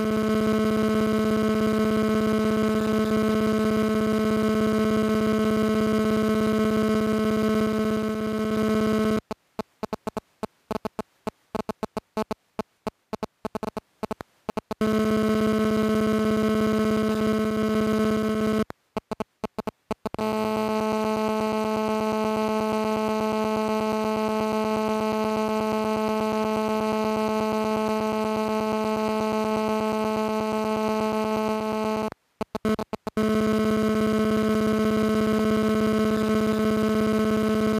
Uplink channel of a GSM network that utilizes a single ARFCN. Includes EDGE data and full-rate calling traffic.
GSM_Uplink_nonhopping.wav